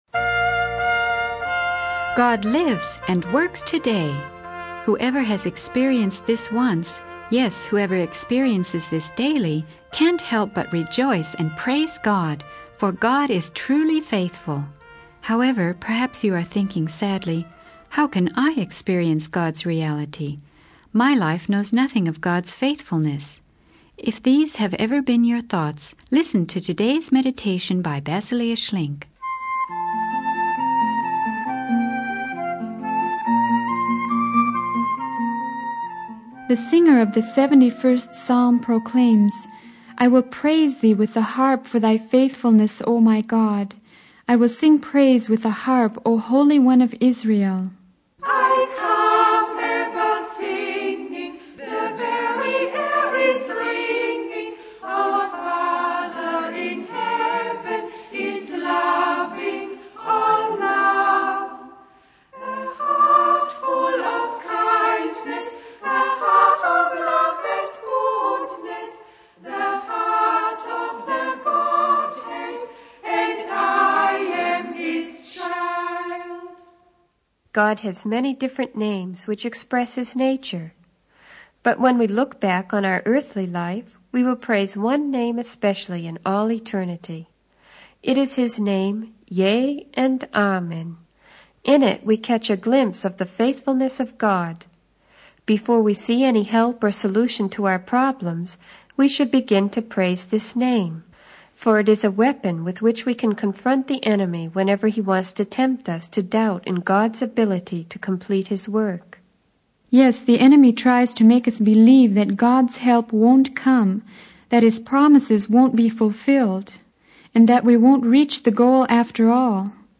She highlights the importance of praising God for his faithfulness and sings praises to him.